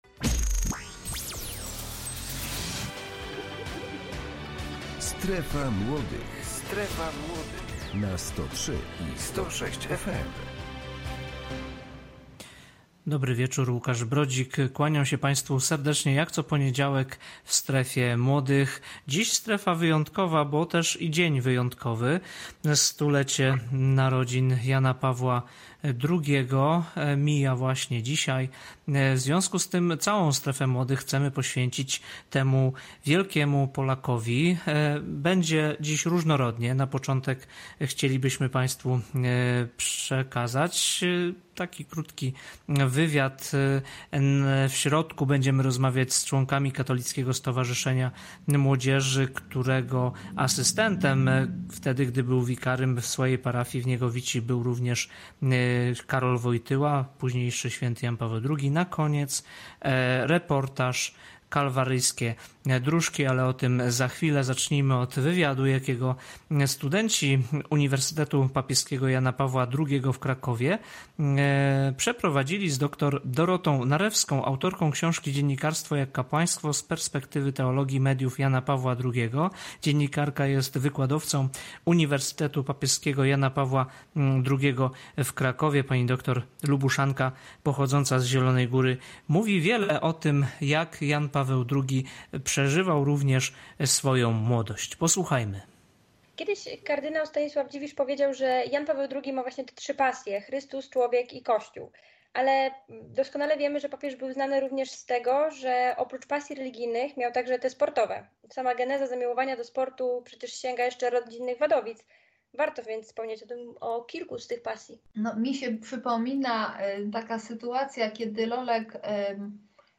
– rozmowa z członkami Katolickiego Stowarzyszenia Młodzieży Diecezji Zielonogórsko-Gorzowskiej o roli, jaką odgrywa Jan Paweł II w życiu młodych ludzi; biorą udział: